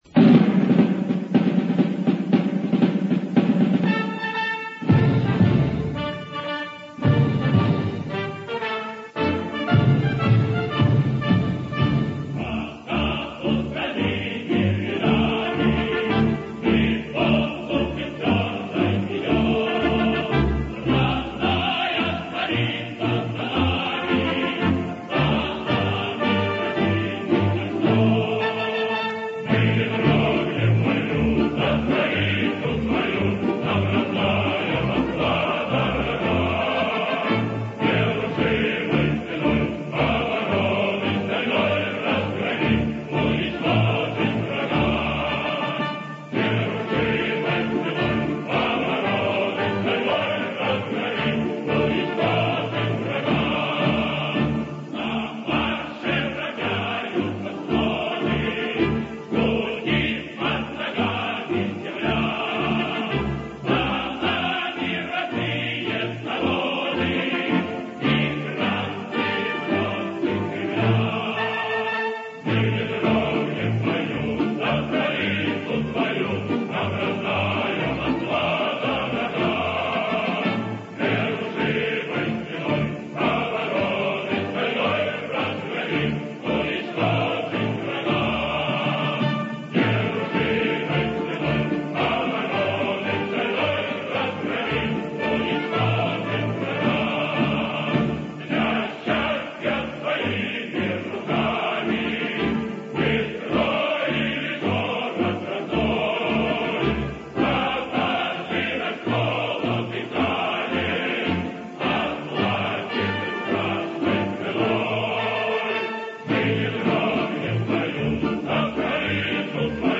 Военные марши (.MP3) [22]